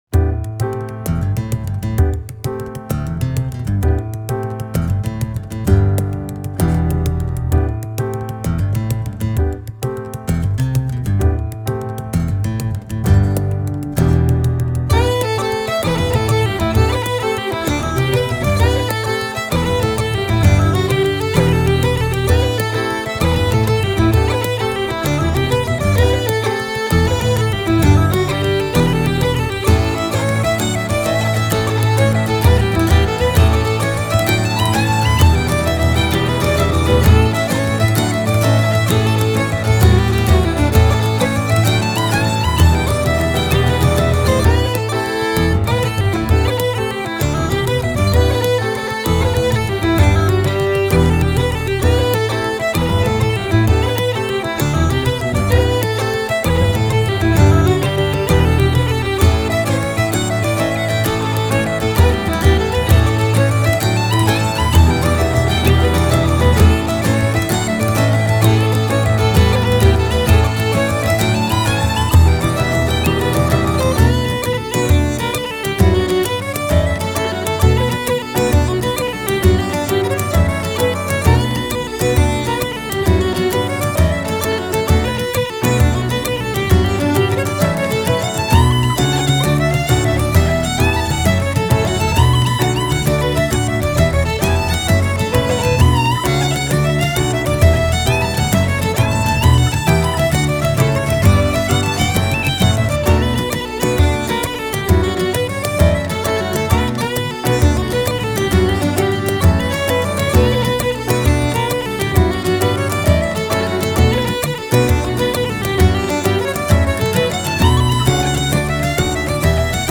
Genre: World, Folk, Celtic, Contemporary Celtic